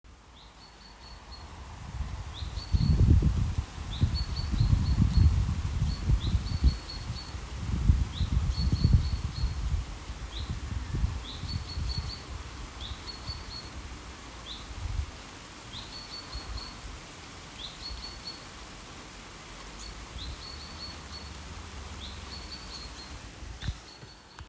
Uopphørlig kvitring/varsling
I Horten kommune i Vestfold er det en fugl som har kvitret/varslet uopphørlig i noen uker nå.
Den befinner seg i en liten hundremeterskog med løvtre nær bebyggelse.
Dette er bokfink varsling.
uopph__rlig_kvitring.m4a